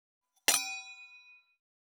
322クリン,シャリン,チキン,コチン,カチコチ,チリチリ,シャキン,
コップワイン効果音厨房/台所/レストラン/kitchen室内食器
コップ